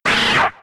Cri de Rattatac K.O. dans Pokémon X et Y.